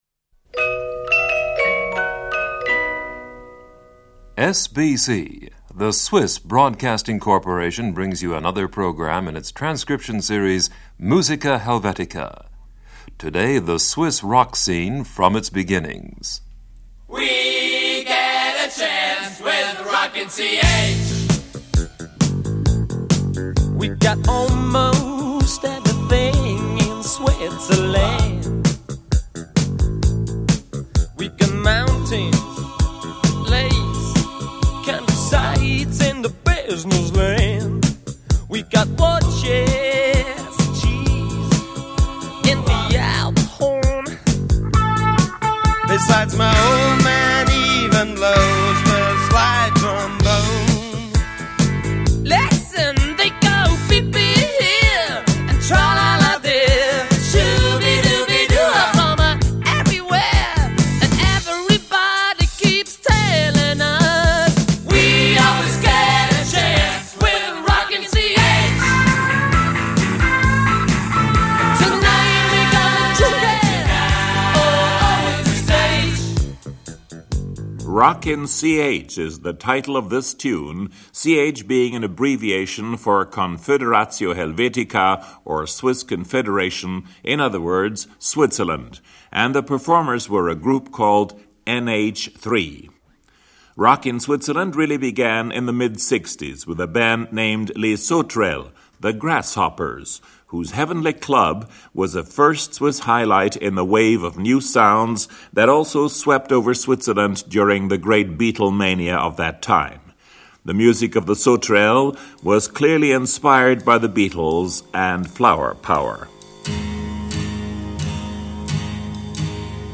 It outlines the development of serious music in Switzerland from earliest liturgic songs to the classical sounds of an 18th century opera buffa on a rustic Swiss theme.